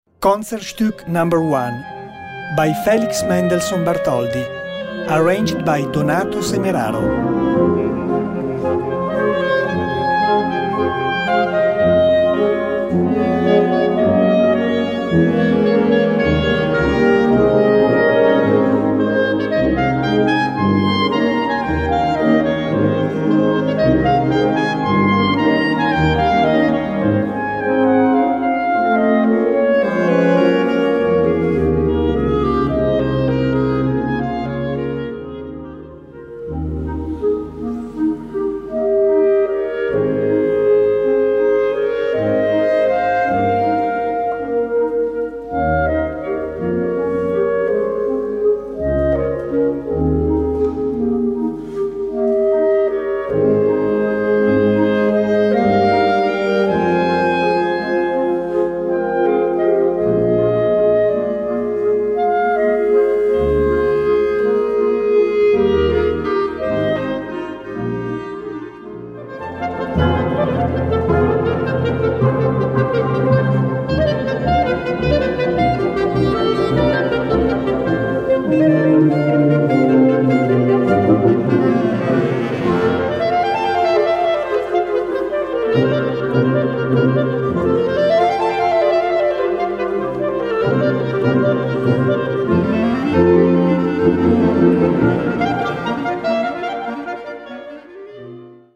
Gattung: Solo für zwei Klarinetten und Blasorchester
Besetzung: Blasorchester